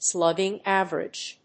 アクセントslúgging àverage [percèntage]